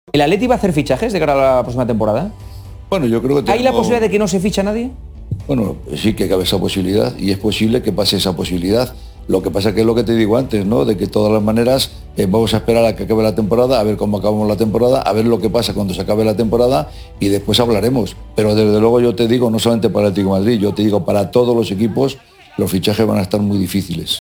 (Enrique Cerezo, presidente del Atlético de Madrid)